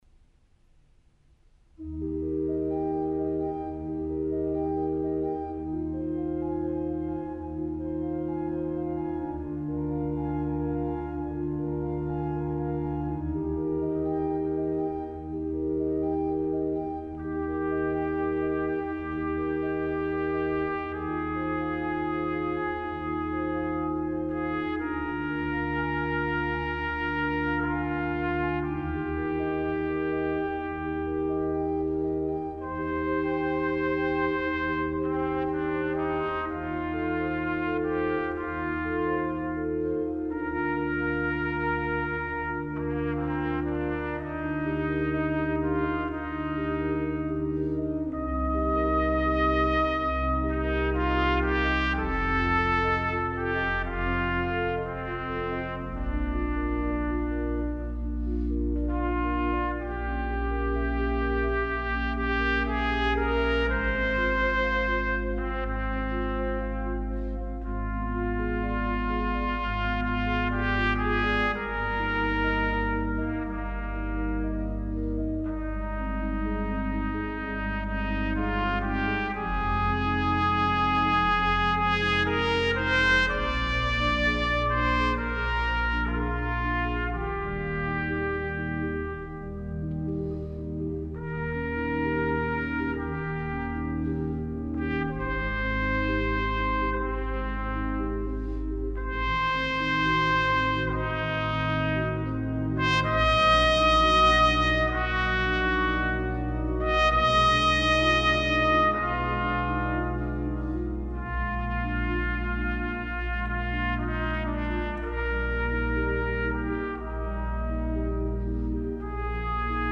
Ave Maria (tpt & organ) - Schubert AM.mp3